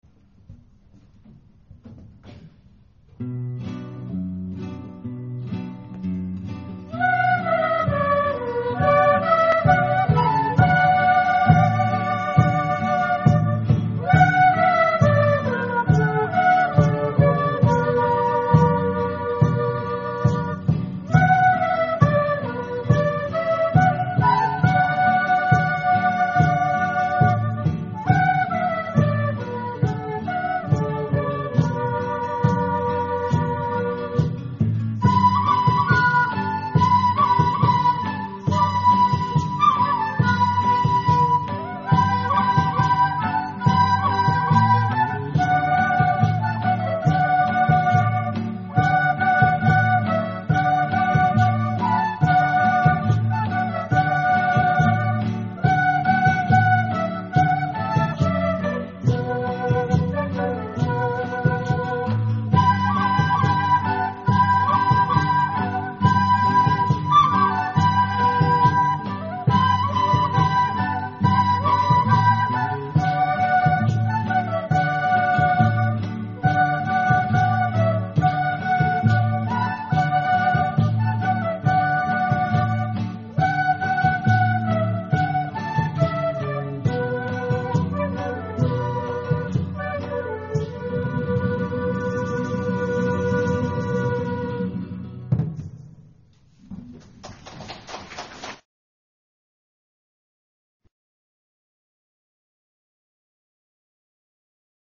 オカリナ曲名 ケーナ・サンポーニャ曲名